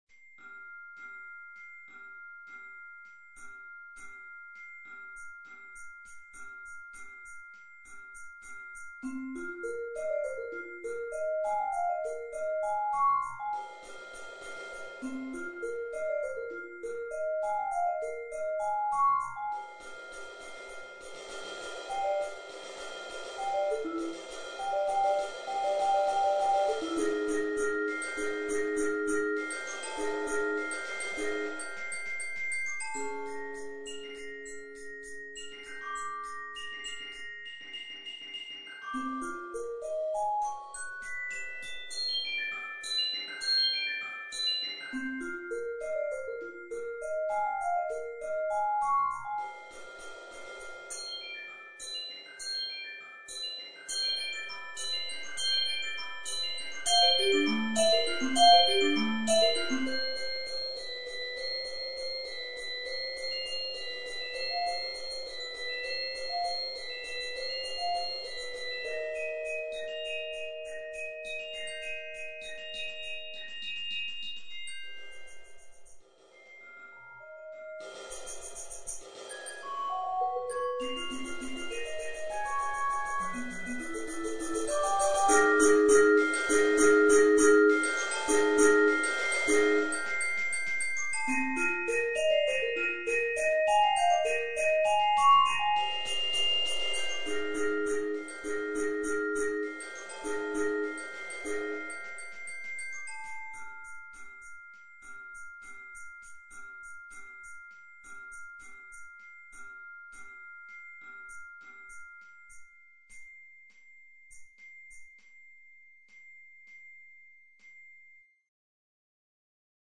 Haiku #1 for Percussion Sextet
A three movement poem from six percussionists
percussion, haiku, composition, symphonic poem